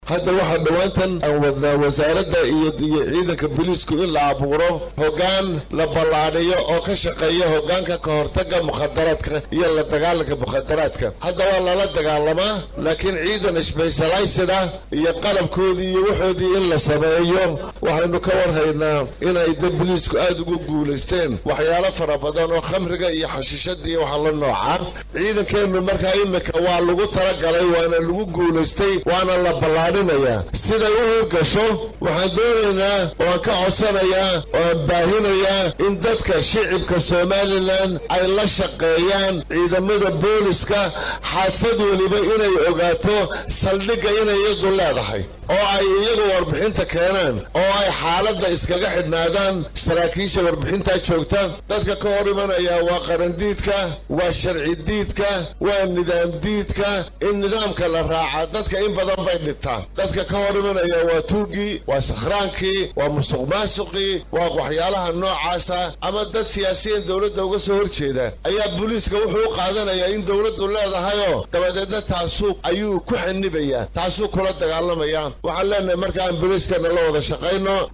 Wasiirka arrimaha gudaha jamhuuriyadda iskeed madax bannaanida ugu dhawaaqday ee Soomaaliland Maxamad Kaahin Axmad oo warbaahinta la hadlay ayaa sheegay inay Soomaaliland sameynayso hoggaanka a dagaalanka maandooriyah oo uu sheegay inuu deegaanadooda dhiibaato wayn ku hayo.